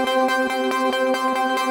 SaS_MovingPad01_140-C.wav